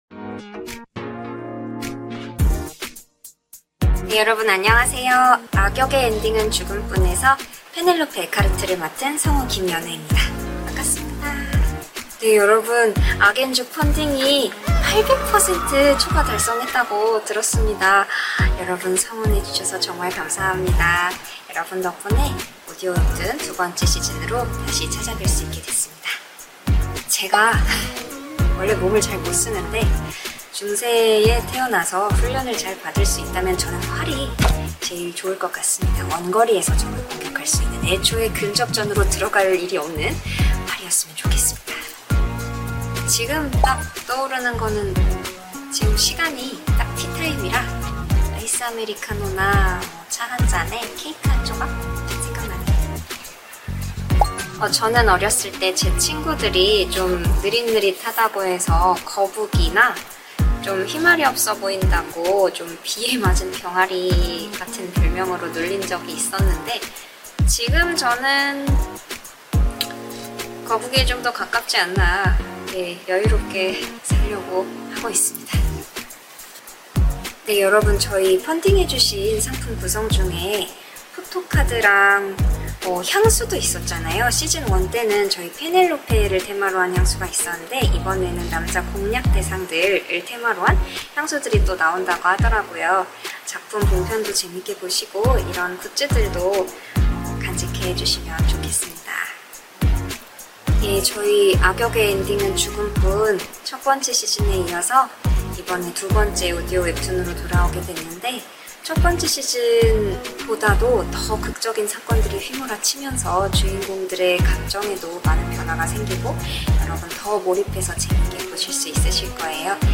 A Surprise Interview